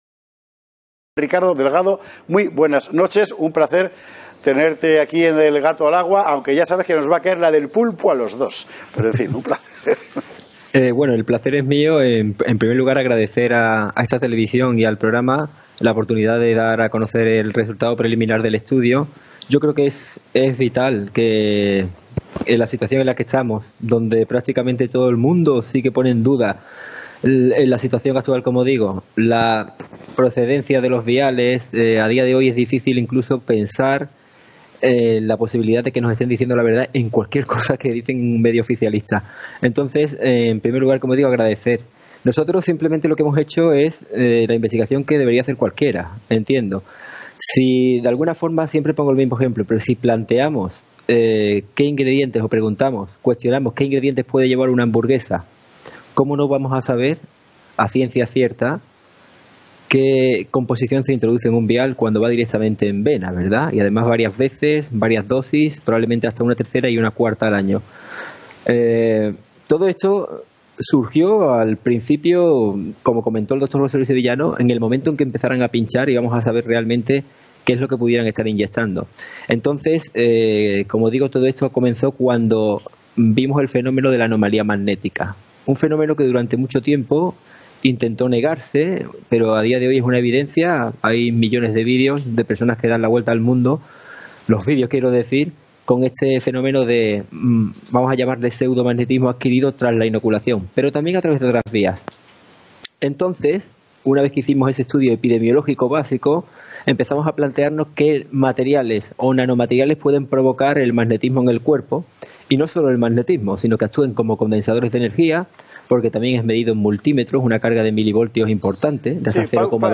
Orwell City has summarized, transcribed, translated, edited and subtitled the video in English for its readers.